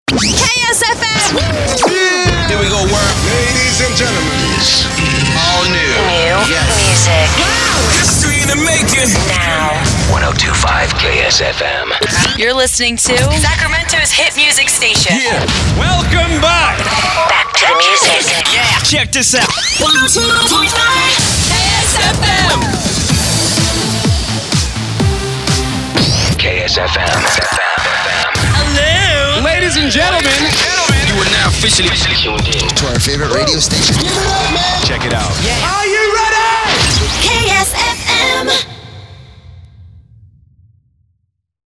CHR Top 40/Mainstream/Rhythmic
Following in the footsteps of great stations like Z100 New York and KIIS FM Los Angeles, Phantom Producer CHR offers slick, up-tempo imaging that's produced to meet major market standards.